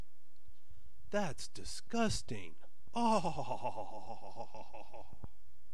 Consider this commonplace media cliche: the particular bellow of disgust that a frat boy, or more typically a group of frat boys, emits when encountering the idea of something that is not as sexually/gastronomically appealing as they perhaps feel entitled to bear in their presence. It is a very particular sound.
(I couldn’t quite pull it off with the full gusto you usually encounter it with, so I kind of went surfer dude with it. No that is not how my voice usually sounds in real life.)
“Oh-oh-oh-oh-oh-oh-oh,” literally, using a hard “o” as in “box”, which is the sound.
disgusting.mp3